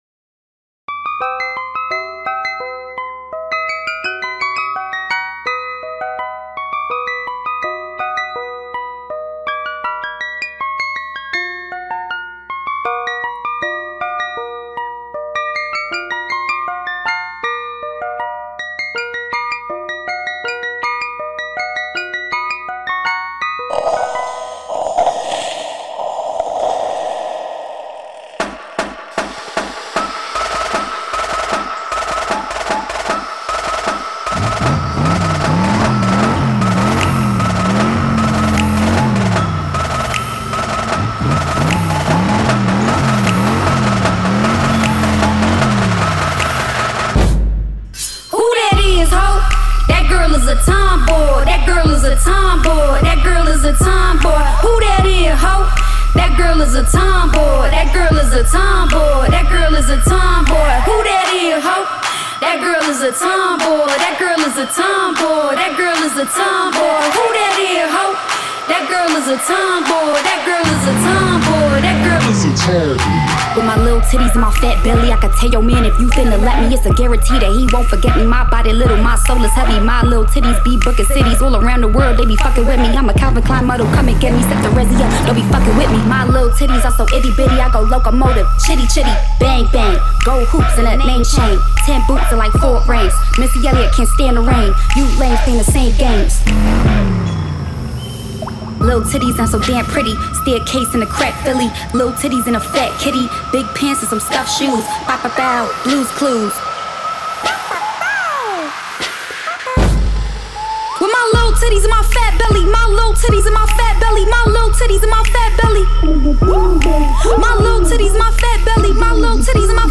こちら昨今の女性アーティストたちの勢いそのまま、明るく楽しくいかつい作品となっております。